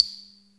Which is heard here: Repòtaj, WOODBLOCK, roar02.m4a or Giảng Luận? WOODBLOCK